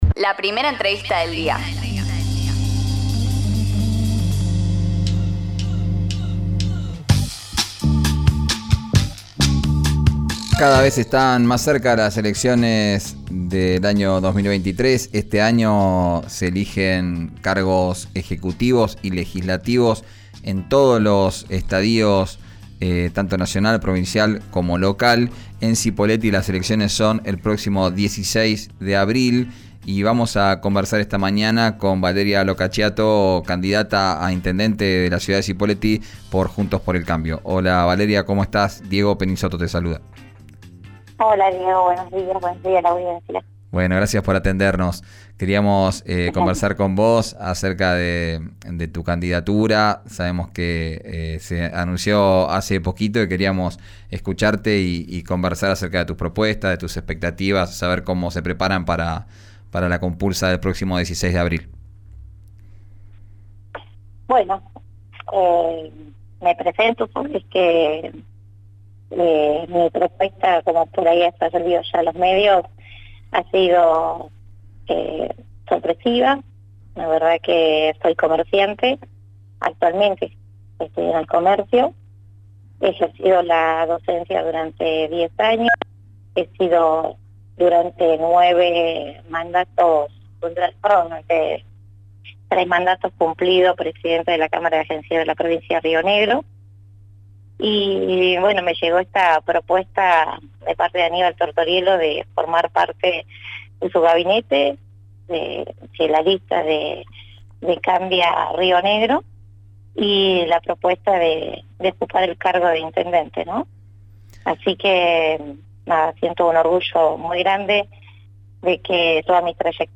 Esta mañana en diálogo con RÍO NEGRO RADIO